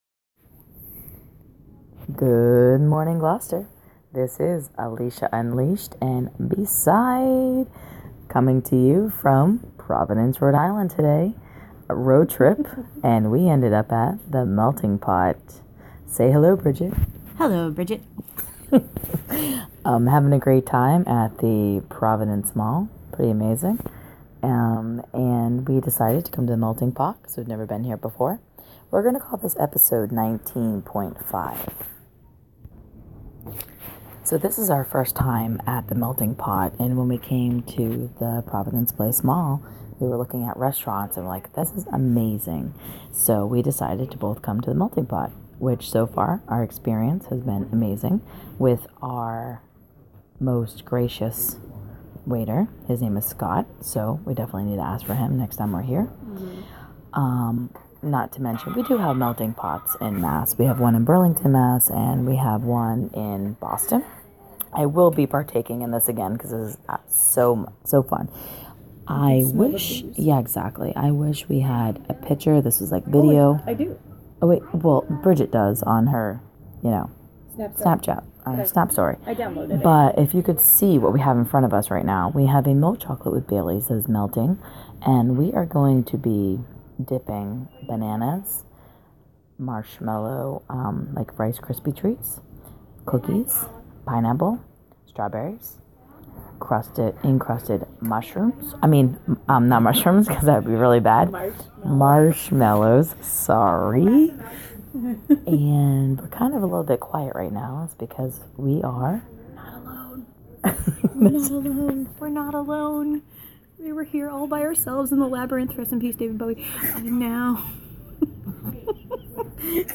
taped a small episode while on a road trip Saturday